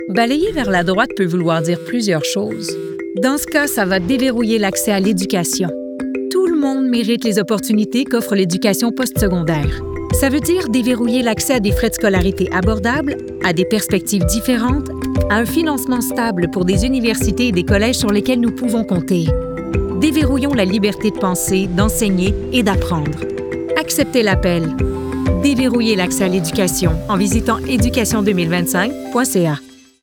Publicité - Démo Voix complet